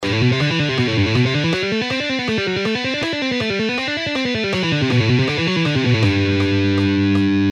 Legato Exercise in G Major Scale:
Original Speed:
3.-Legato-Exercise-In-G-Major-Scale.mp3